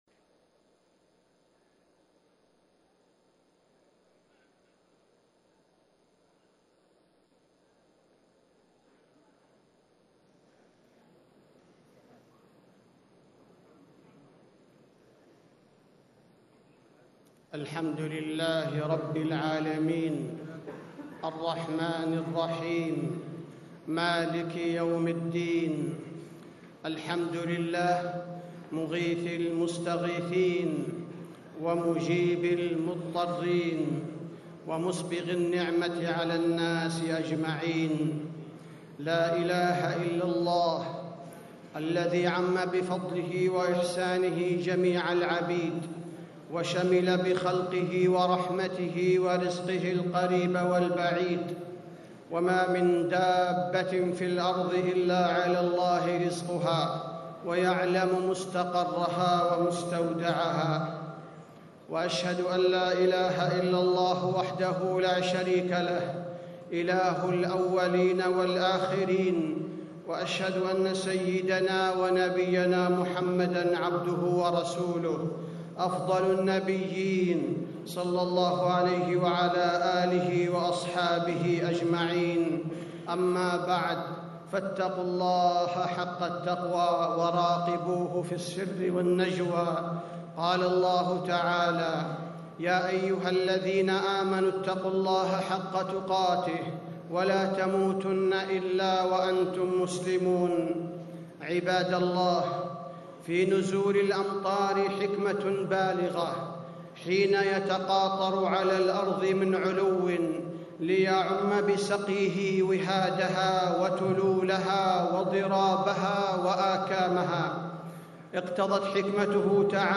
خطبة الاستسقاء - المدينة- الشيخ عبدالباريء الثبيتي
تاريخ النشر ٤ ربيع الثاني ١٤٣٧ هـ المكان: المسجد النبوي الشيخ: فضيلة الشيخ عبدالباري الثبيتي فضيلة الشيخ عبدالباري الثبيتي خطبة الاستسقاء - المدينة- الشيخ عبدالباريء الثبيتي